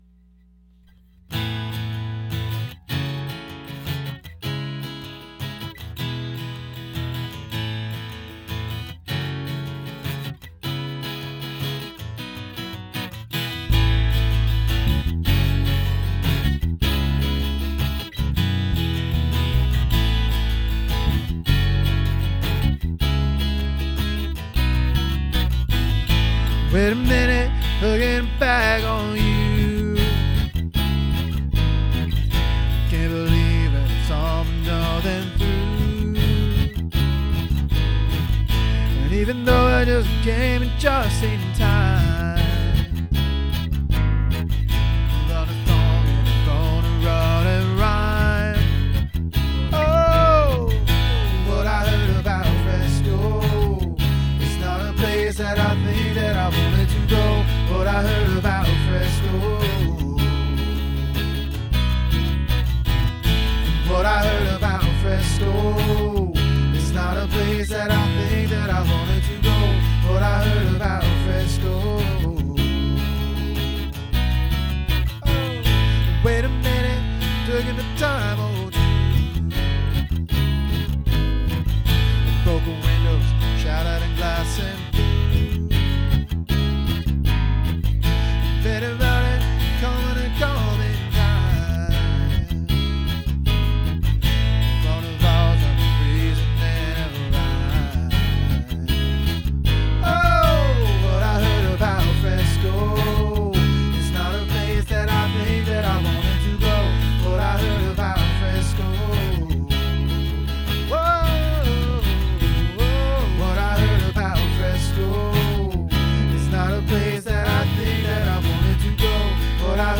Hi, I've written a song and am looking for help with lyrics. I've uploaded it with scratch vocals below.